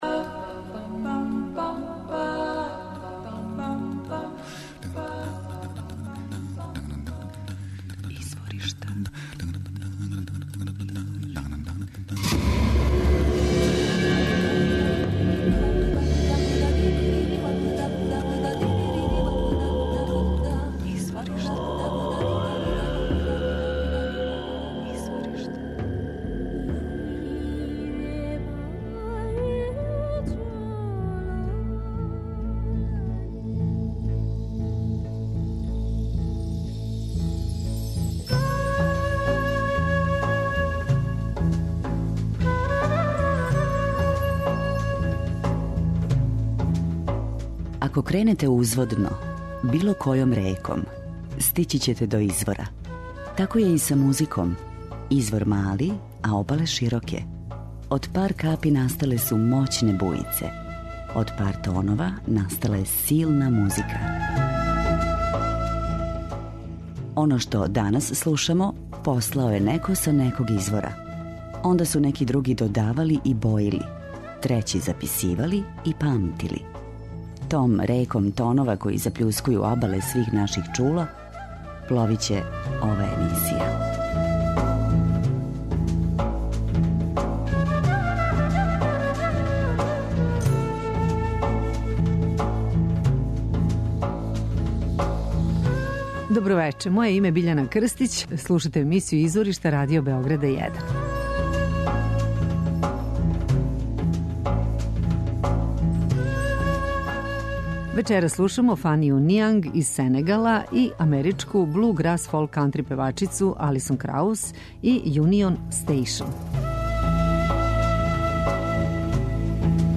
америчку фолк кантри певачицу
мандолину, клавир и виолину